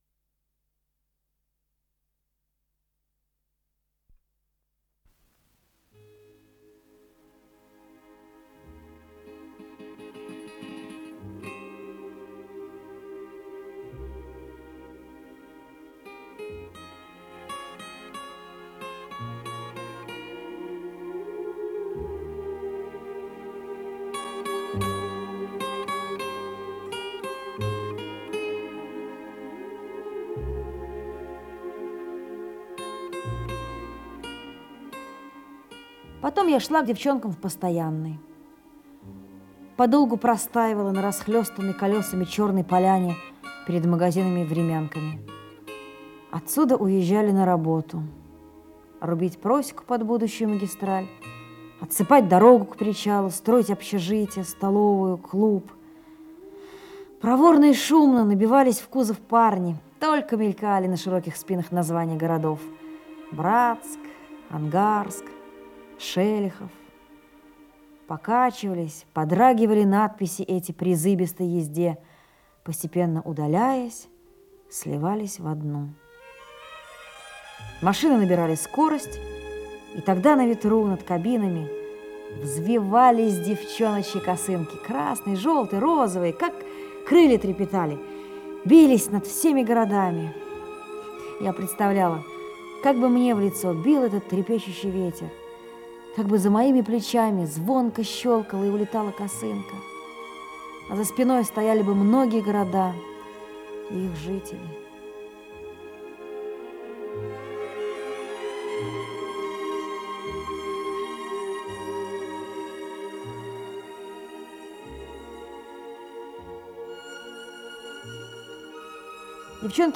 Радиопьеса